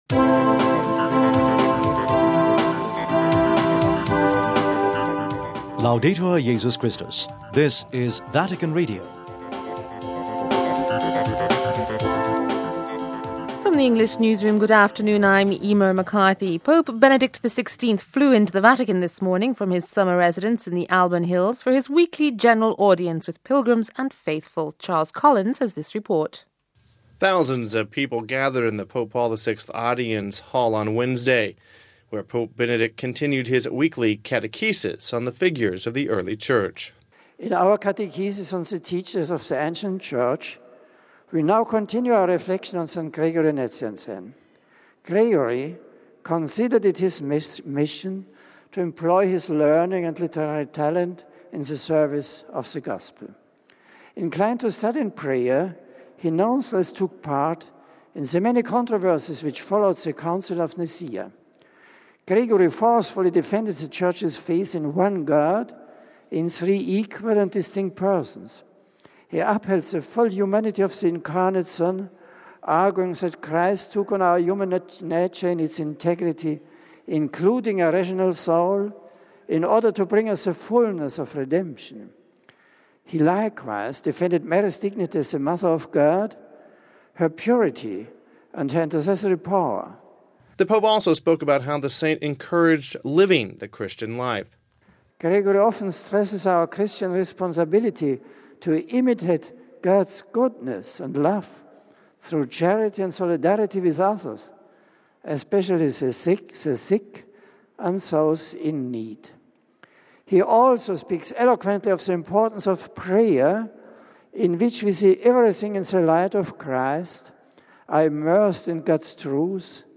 Home Archivio 2007-08-22 15:16:19 Pope Benedict XVI Holds Weekly General Audience (22 Aug 07 - RV) Pope Benedict XVI continued his catechesis on the figures of the early Church during his Wednesday Audience. We have this report...